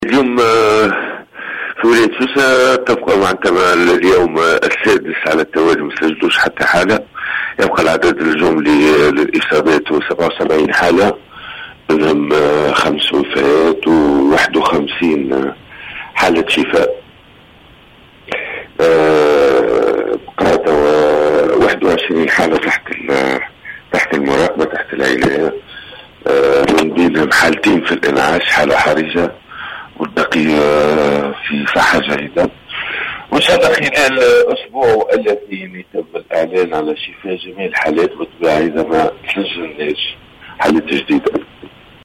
أعلن المدير الجهوي للصحة بسوسة، سامي الرقيق في تصريح لـ"الجوهرة أف أم" اليوم الأحد، عدم تسجيل أية إصابات جديدة بفيروس "كورونا" المستجد، وذلك لليوم السابع على التوالي.